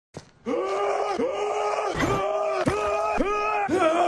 Memes
Bernthal Scream